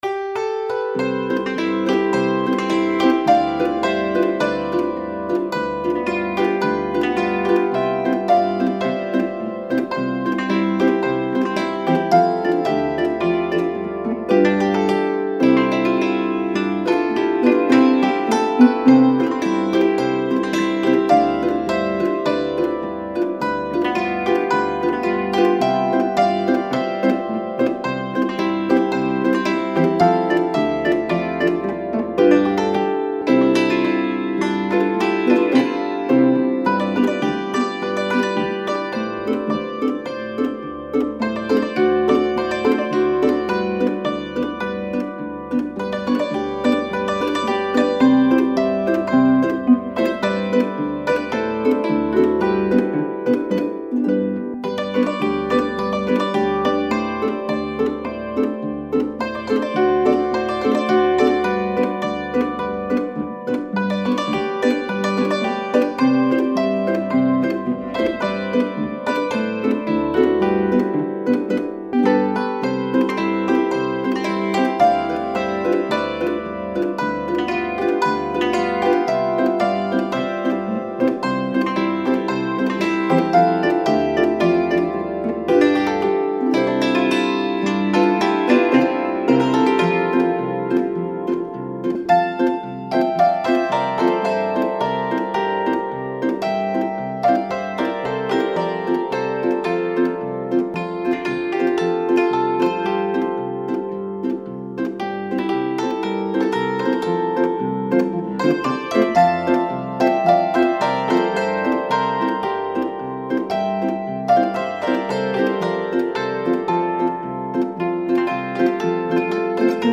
volksmusikalische Melodien